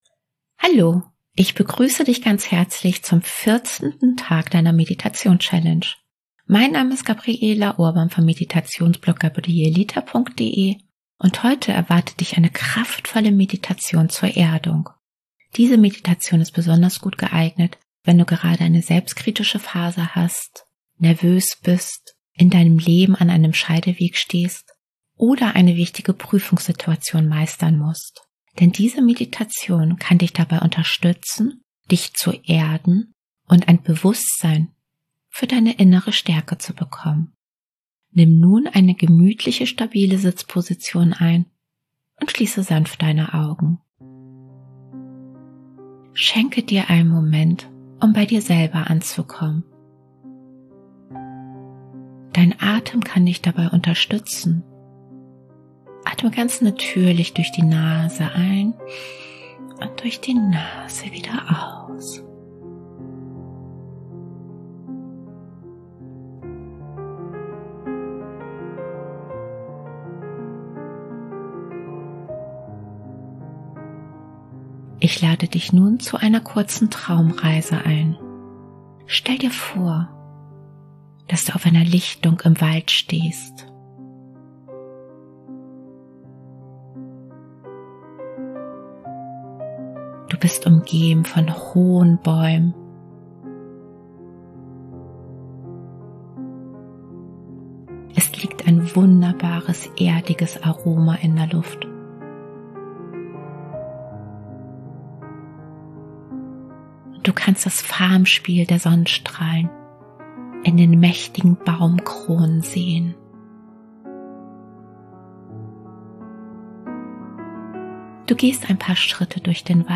Traumreisen & geführte Meditationen